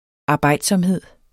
Udtale [ ɑˈbɑjˀdsʌmˌheðˀ ]